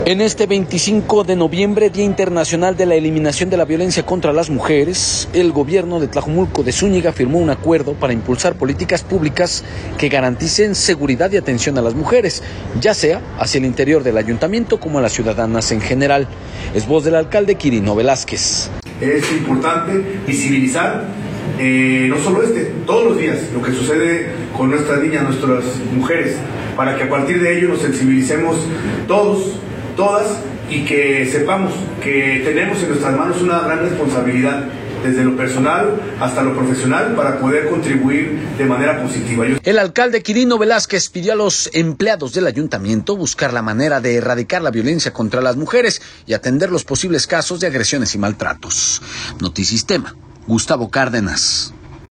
En este 25 de noviembre, Día Internacional de la Eliminación de la Violencia contra las Mujeres, el Gobierno de Tlajomulco de Zúñiga firmó un acuerdo para impulsar políticas públicas que garanticen seguridad y atención a las mujeres, ya sea al interior del ayuntamiento como a las ciudadanas en general, es voz del alcalde Quirino Velázquez.